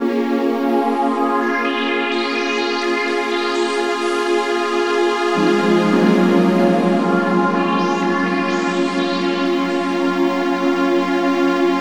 03D-PAD-.A-R.wav